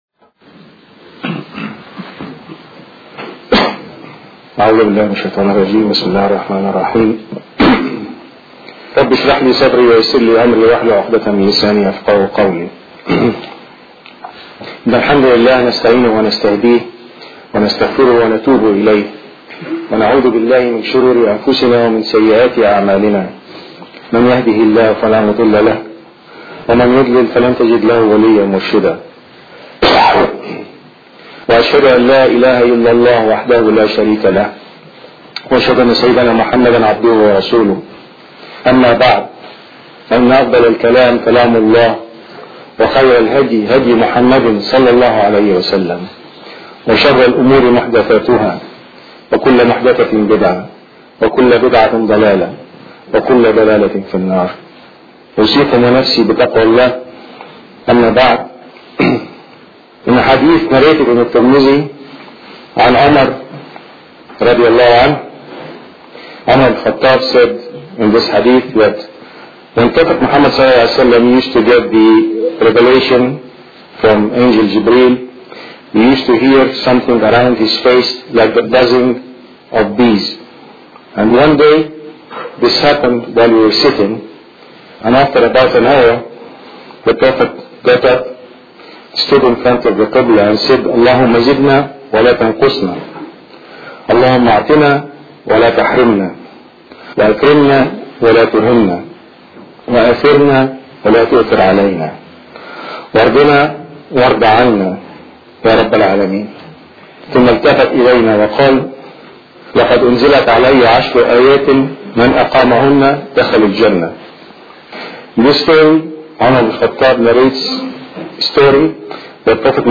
To listen to this audio khutbah, please click here: